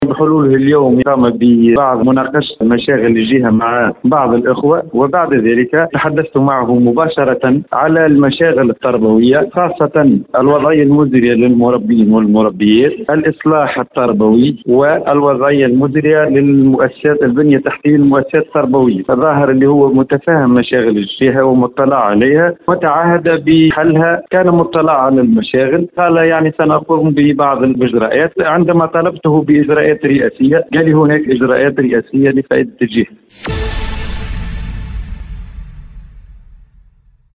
Dans une déclaration à Diwan FM, il a affirmé que le président Saïed a annoncé avoir pris des mesures présidentielles en faveur de Mezzouna.